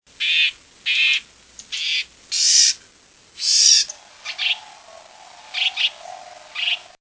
house wren
Buzzes